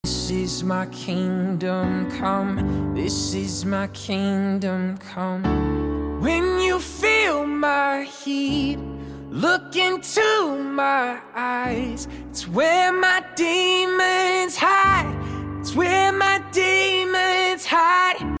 FULL cover streaming everywhere 🖤🤍